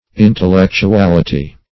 intellectuality - definition of intellectuality - synonyms, pronunciation, spelling from Free Dictionary
Intellectuality \In`tel*lec`tu*al"i*ty\, n. [L.